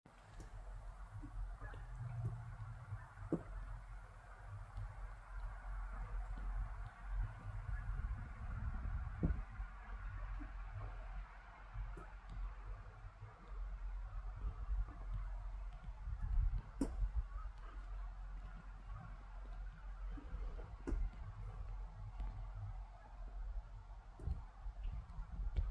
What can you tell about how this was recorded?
Turkey's visit while hunting Deer